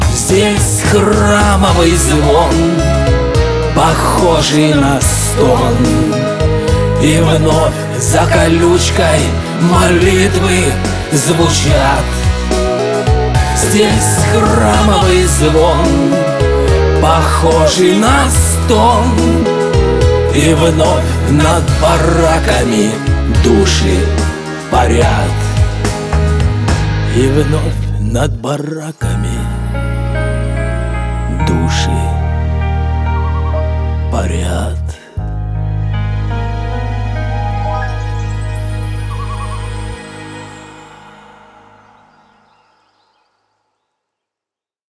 • Качество: 320, Stereo
душевные
русский шансон